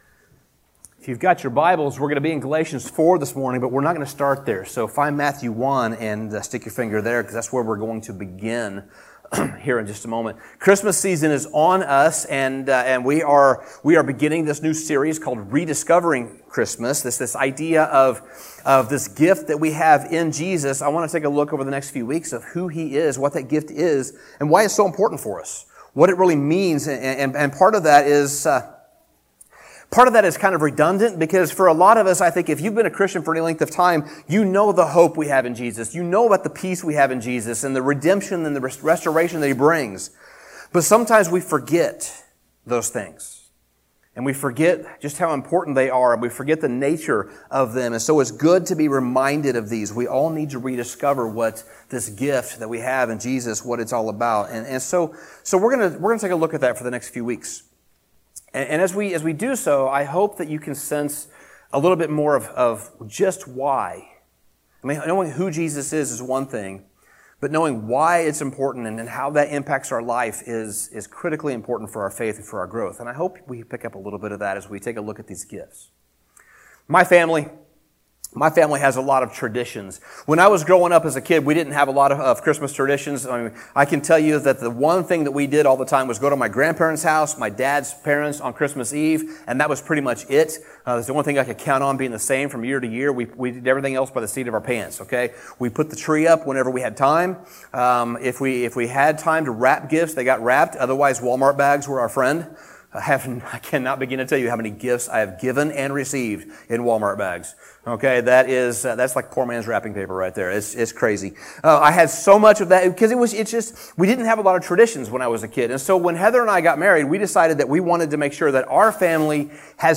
Sermon Summary Christmas is a very busy time of the year.